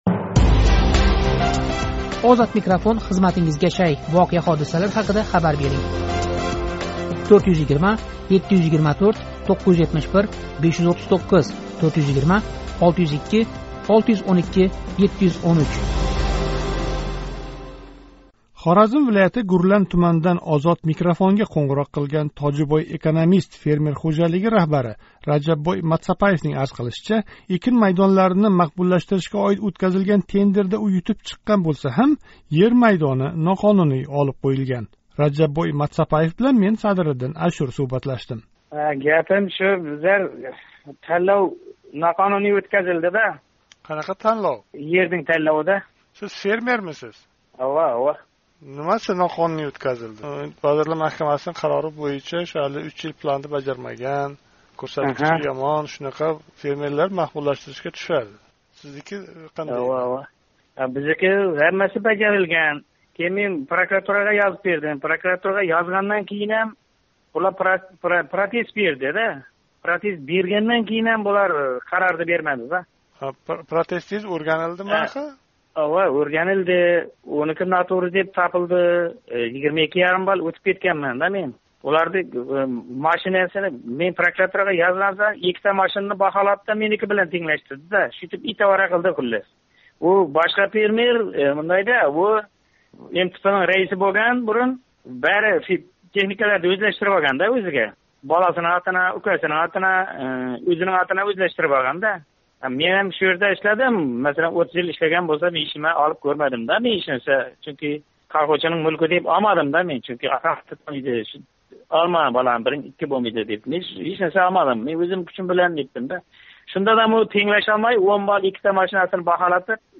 Хоразм вилояти, Гурлан туманидан OzodMikrofonга қўнғироқ қилган